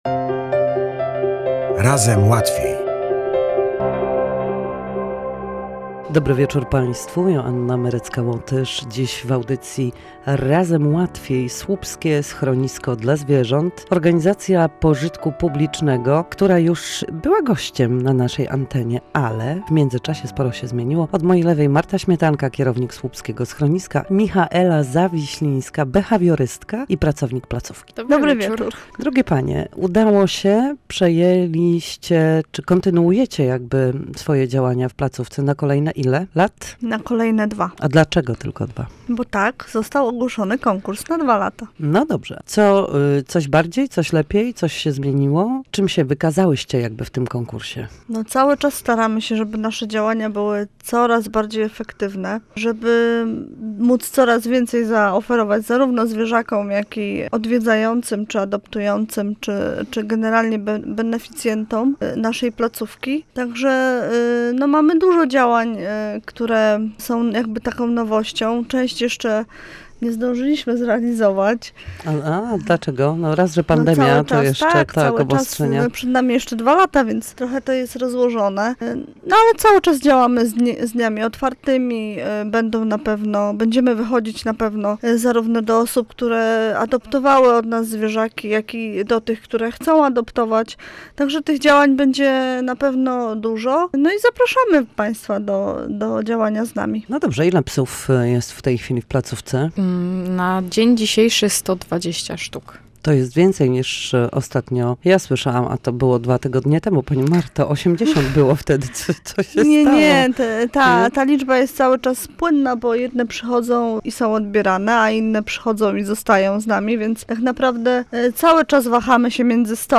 Tym razem w audycji Razem Łatwiej gościliśmy przedstawicieli słupskiego oddziału Towarzystwa Opieki nad Zwierzętami.